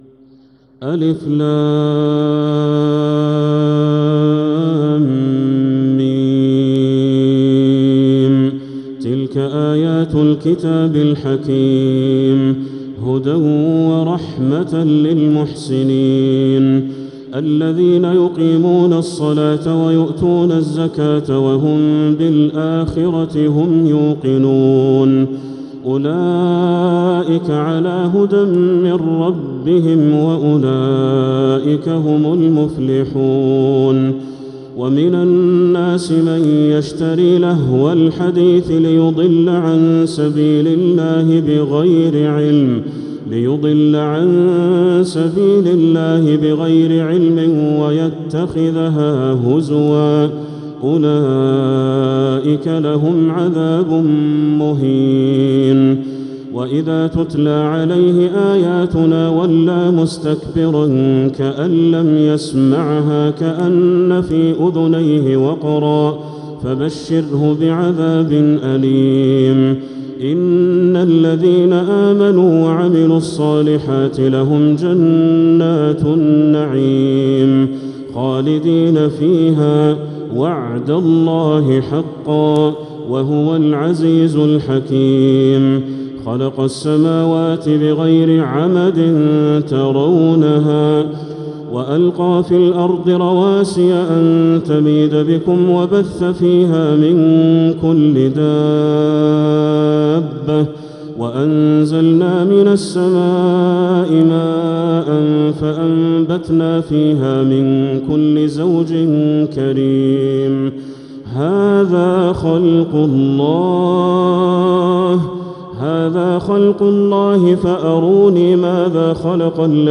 سورة لقمان كاملة | رمضان 1446هـ > السور المكتملة للشيخ بدر التركي من الحرم المكي 🕋 > السور المكتملة 🕋 > المزيد - تلاوات الحرمين